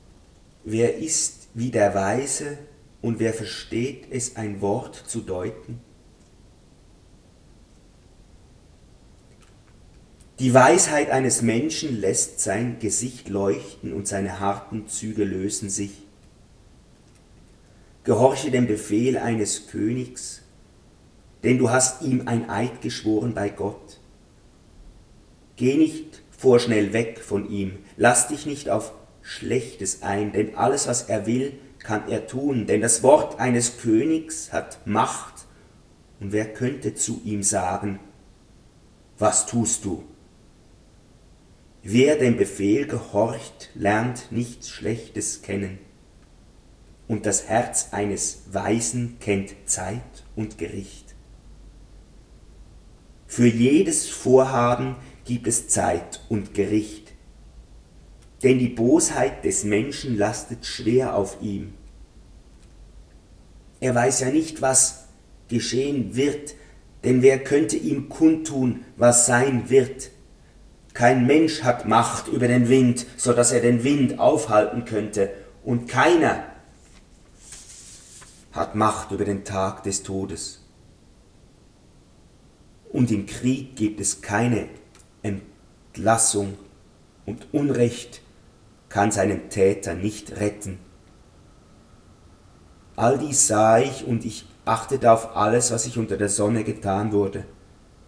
Durchgehende Lesung biblischer Bücher